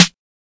Snares
EGE_TRP_SNR.wav